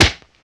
Monster Crash.ogg